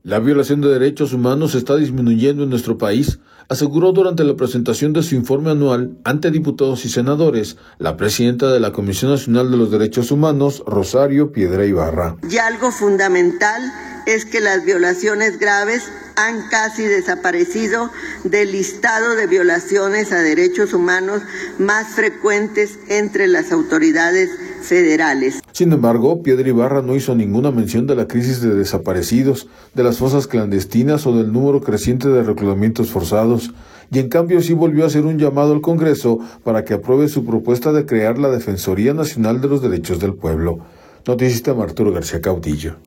La violación de derechos humanos está disminuyendo en nuestro país, aseguró durante la presentación de su informe anual, ante diputados y senadores, la presidenta de la Comisión Nacional de los Derechos Humanos, Rosario Piedra Ibarra.